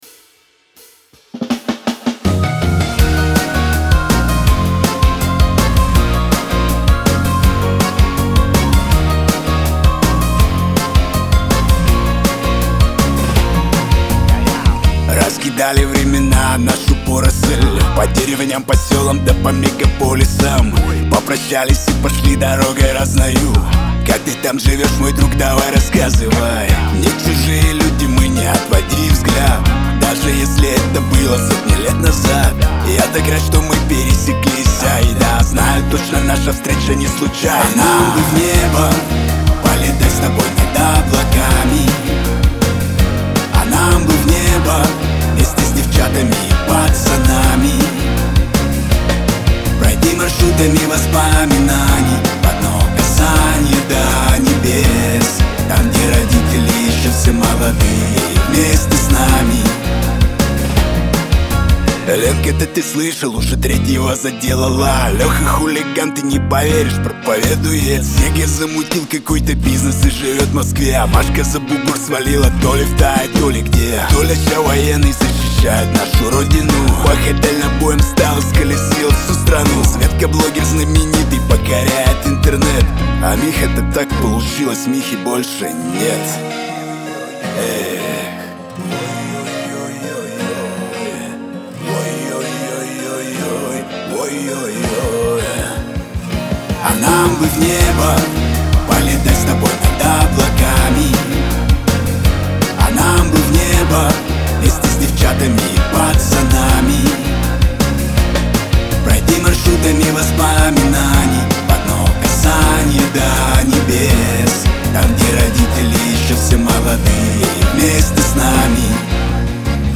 Лирика , ХАУС-РЭП , эстрада